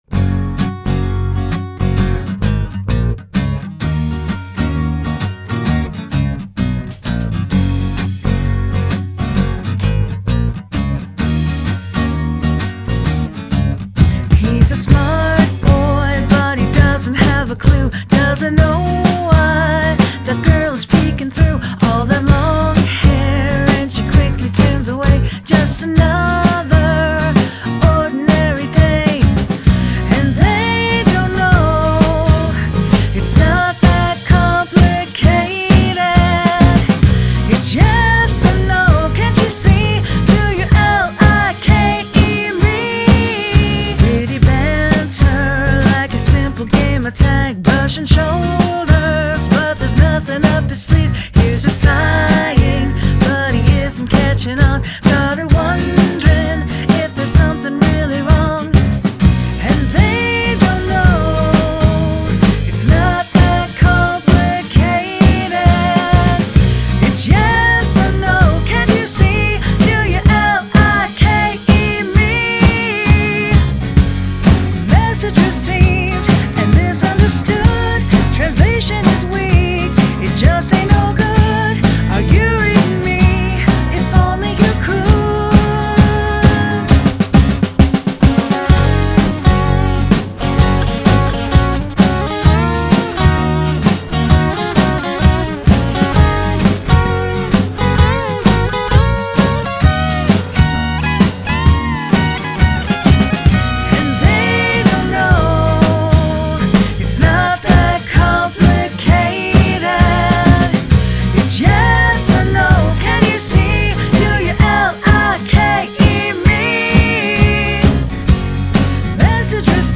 catchy song, I like it.
I like the upward leap in the chorus melody.